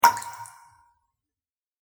環境音 （38件）
ぴちょん単発.mp3